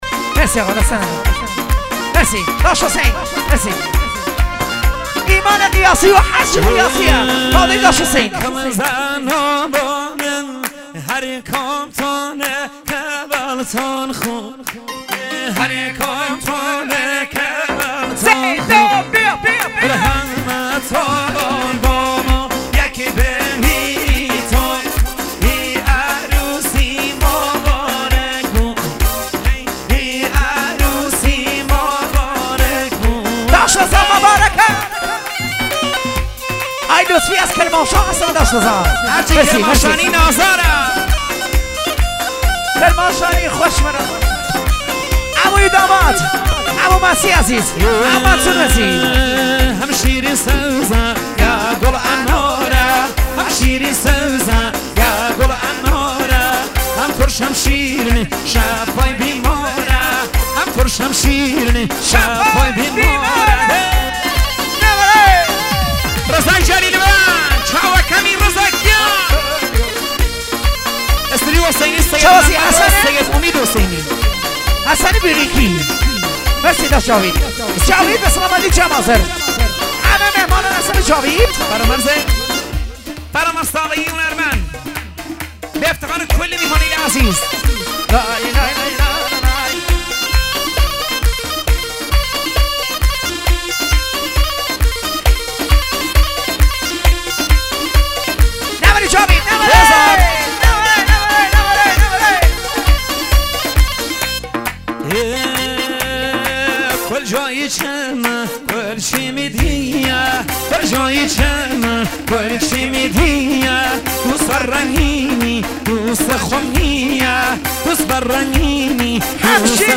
شاد ارکستری کردی